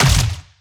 Plasma Rifle
Added more sound effects.
LASRGun_Plasma Rifle Fire_01_SFRMS_SCIWPNS.wav